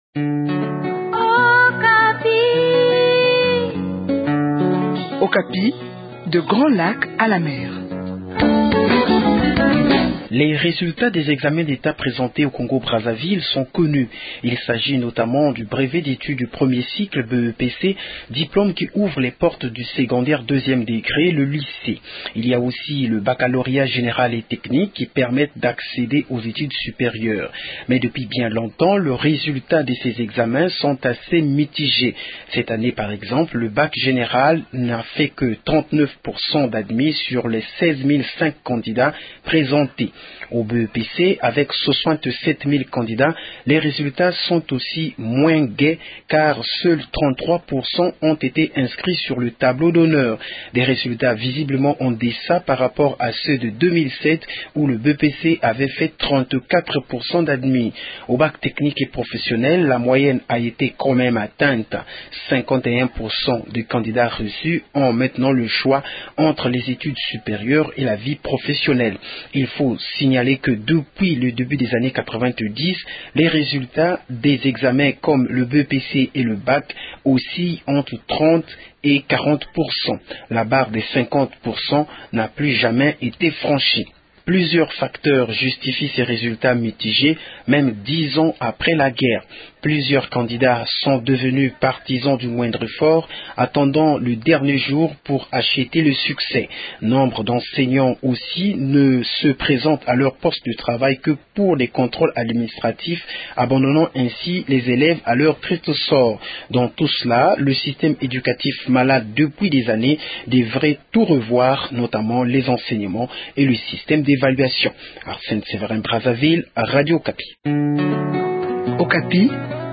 depuis Brazzaville